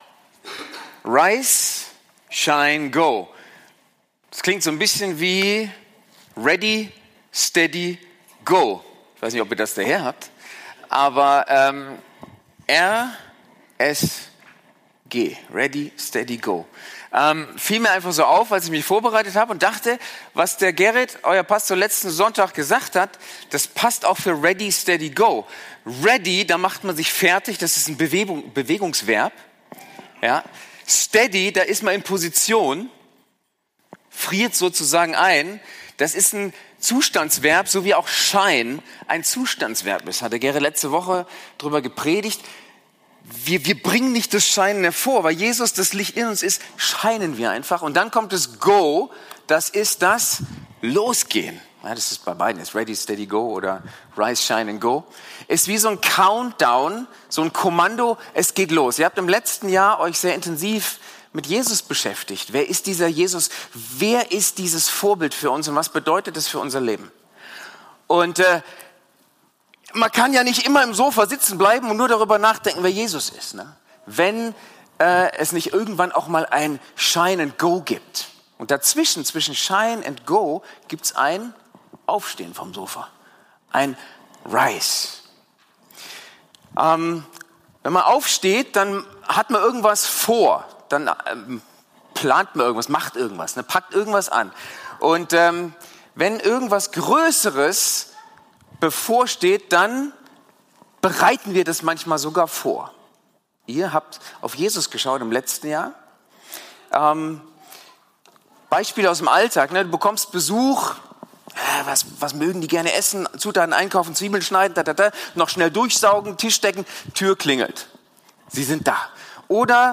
Predigt vom 25.01.2026 in der Kirche für Siegen